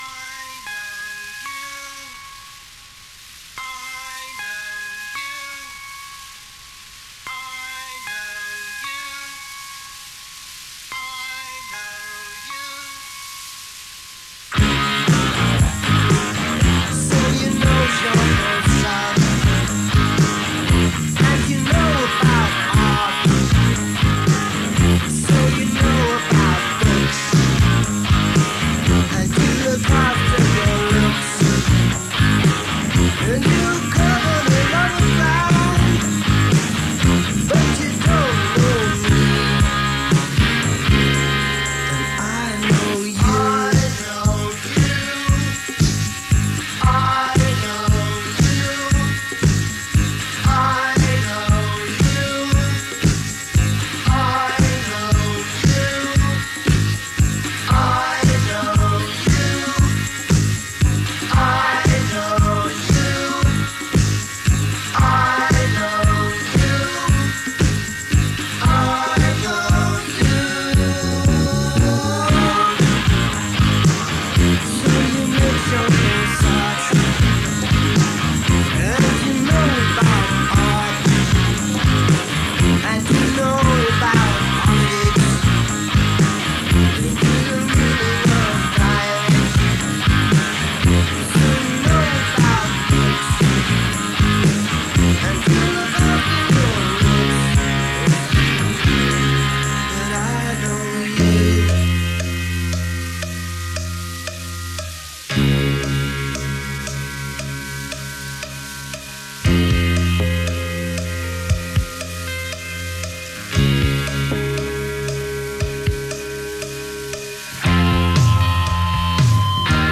Demo.